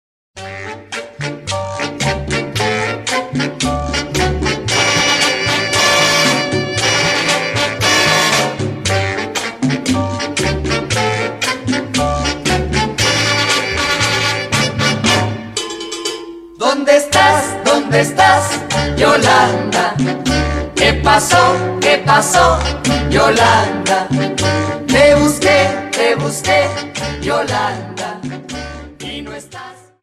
Dance: Cha Cha Song